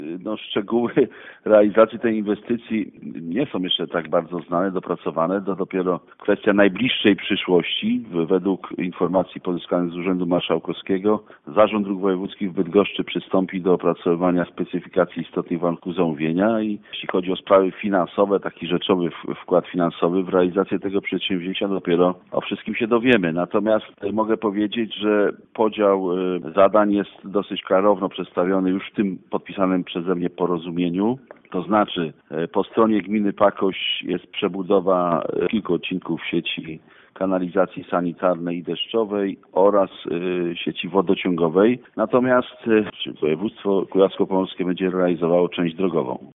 mówił Burmistrz Pakości